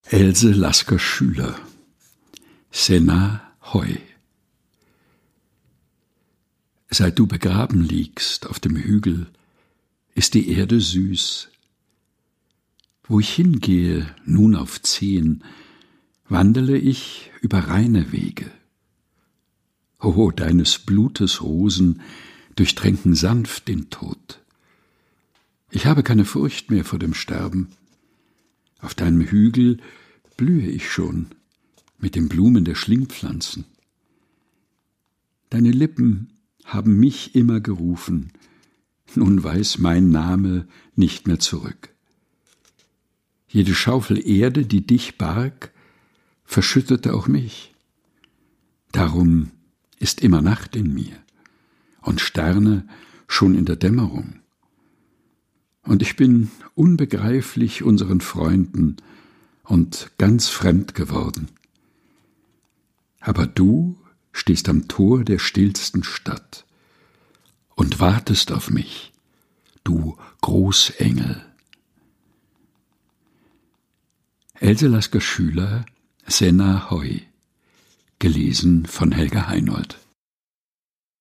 ausgesucht und im Dachkammerstudio vorgelesen